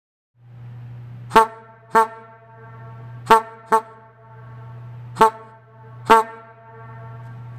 1910-buick-model-f-horn1.mp3